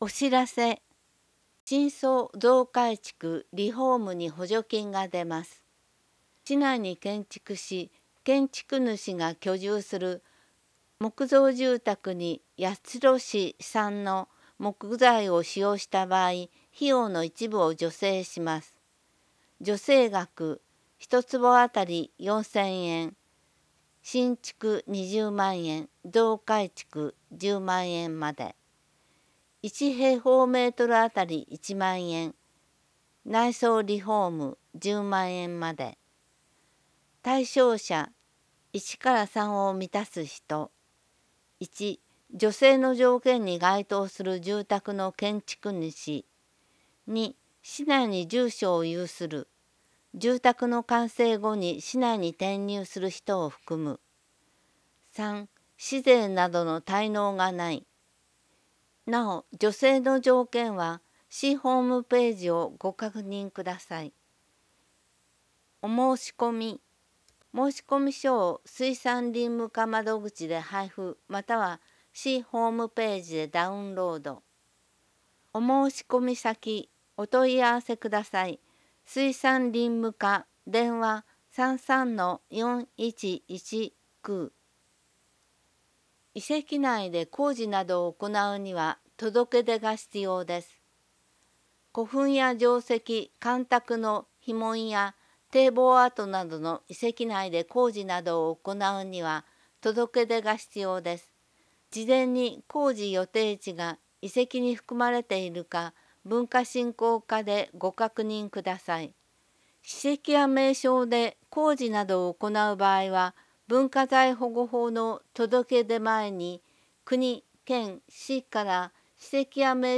声の市報